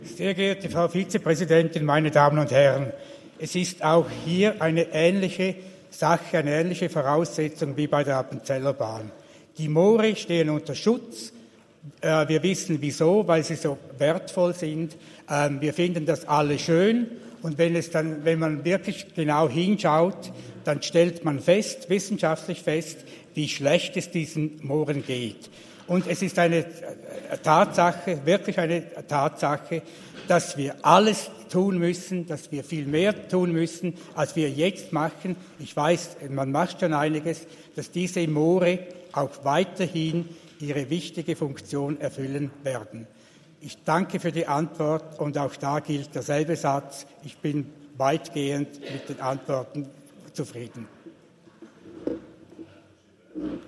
27.11.2023Wortmeldung
Session des Kantonsrates vom 27. bis 29. November 2023, Wintersession